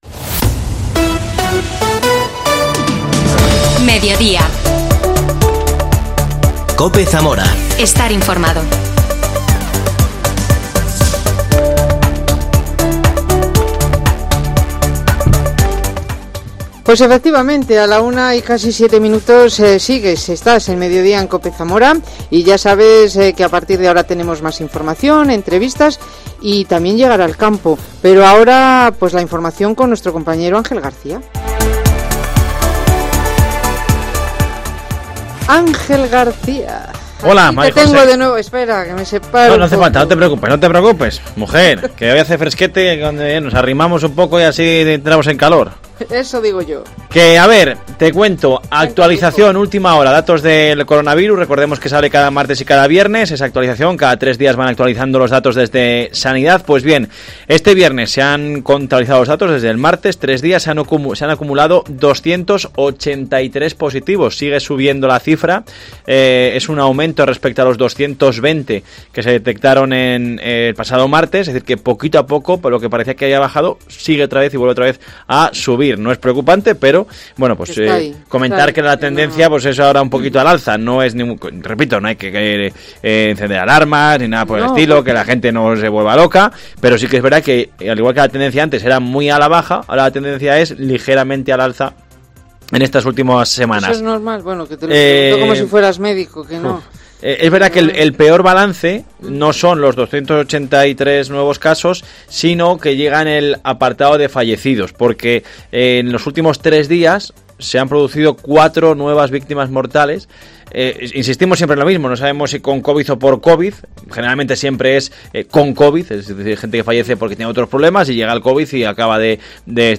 AUDIO: Entrevista al cantante